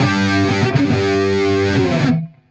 AM_HeroGuitar_95-G02.wav